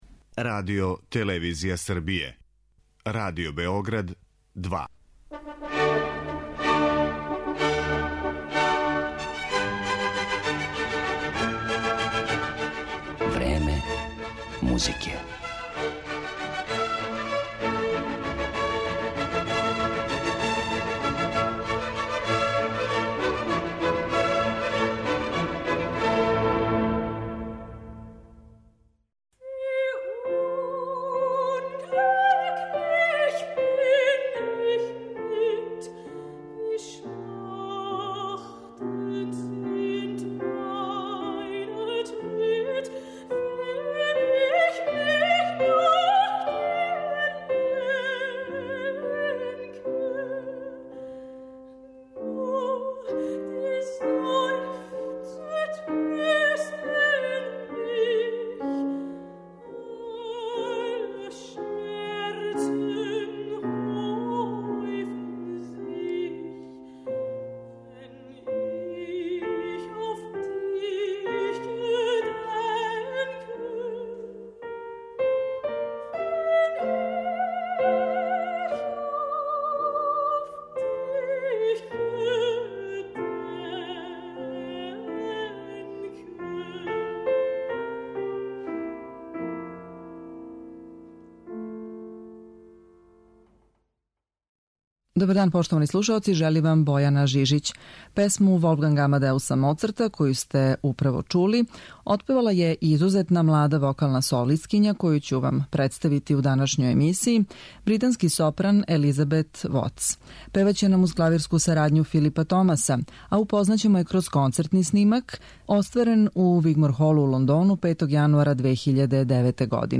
У данашњој емисији слушаћете једну од најатрактивнијих и најуспешнијих вокалних солисткиња млађе генерације у Великој Британији - сопран Елизабет Вотс.